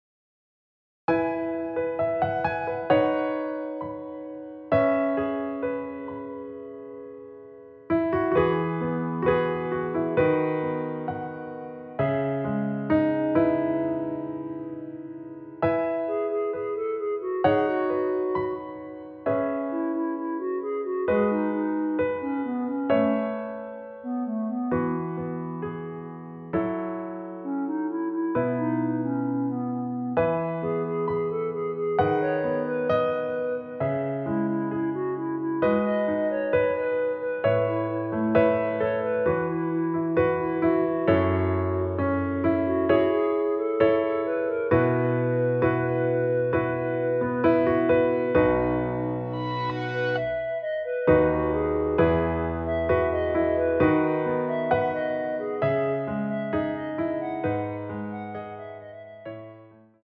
원키에서(-7)내린 멜로디 포함된 MR입니다.
앞부분30초, 뒷부분30초씩 편집해서 올려 드리고 있습니다.
중간에 음이 끈어지고 다시 나오는 이유는